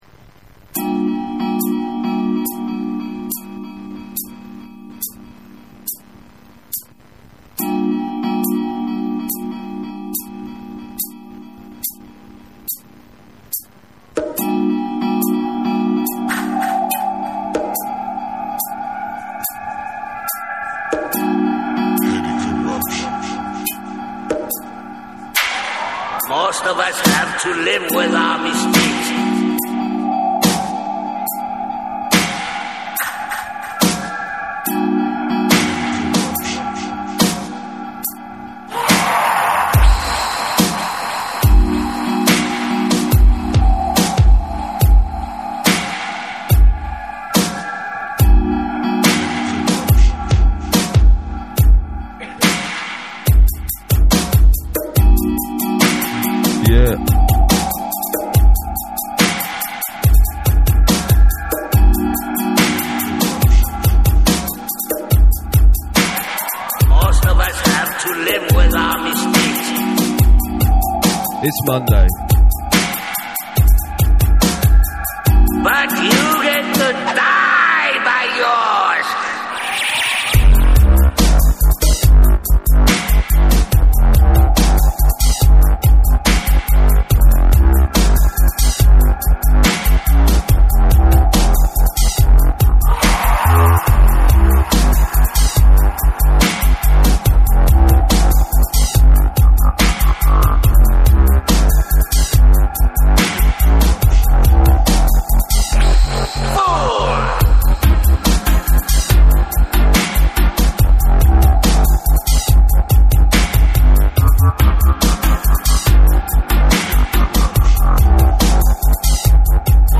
Just a little warm-up